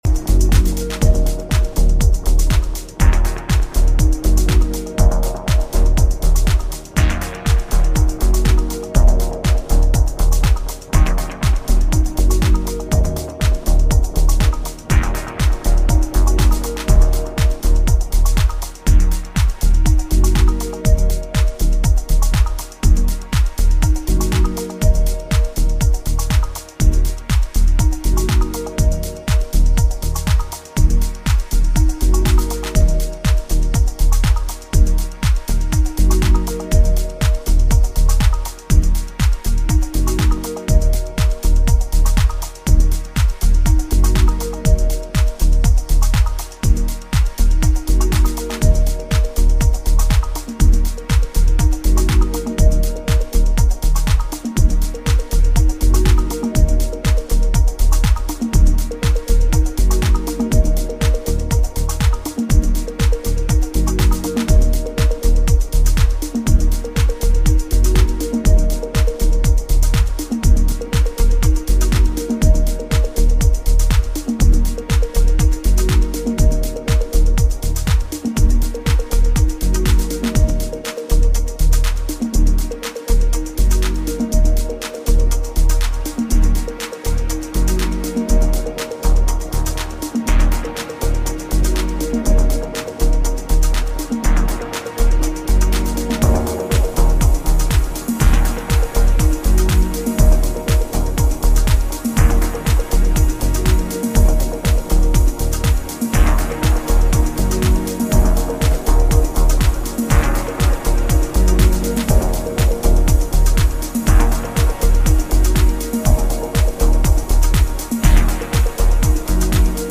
delicate balance of rhythms, melodies and synth work
House Techno